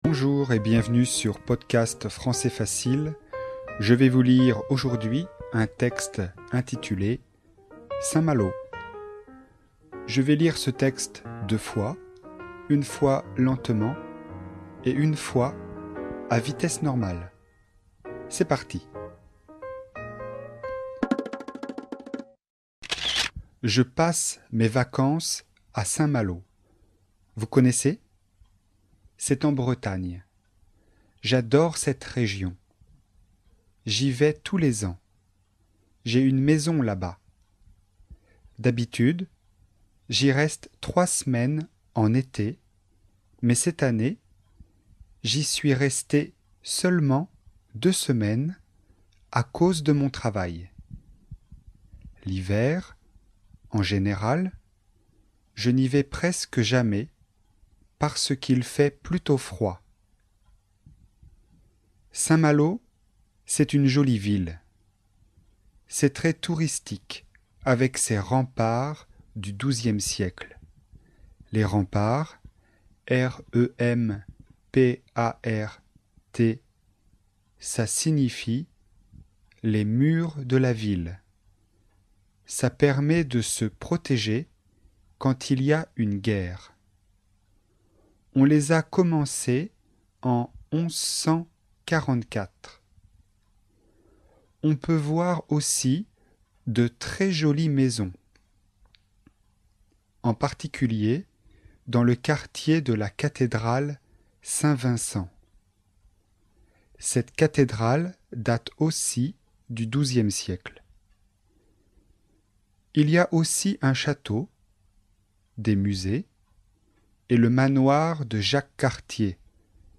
Texte FLE, niveau intermédiaire (A2), sur le thème "ville et région".